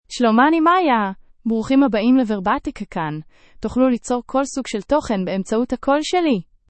Maya — Female Hebrew (Israel) AI Voice | TTS, Voice Cloning & Video | Verbatik AI
Maya is a female AI voice for Hebrew (Israel).
Voice sample
Listen to Maya's female Hebrew voice.
Female
Maya delivers clear pronunciation with authentic Israel Hebrew intonation, making your content sound professionally produced.